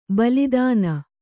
♪ balid`na